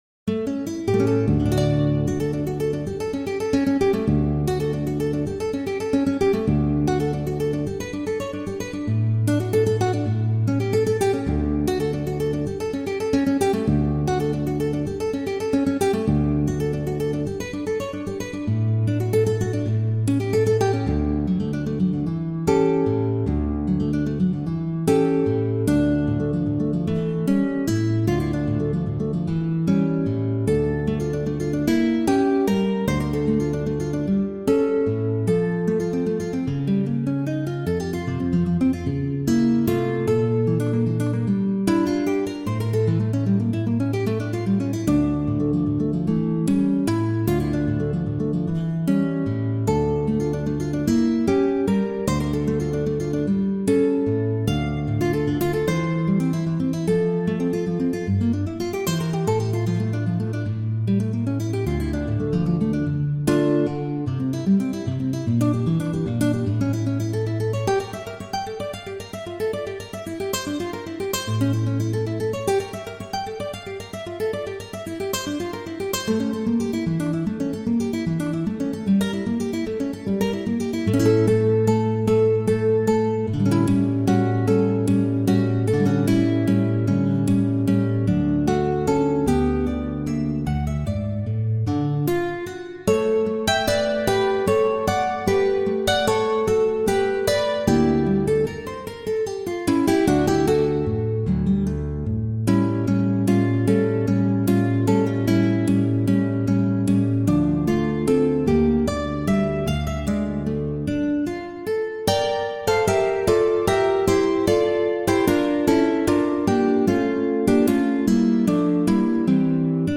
Ses oeuvres gardent cette force puissante et poétique du caractère ibérique même interprétées à la guitare.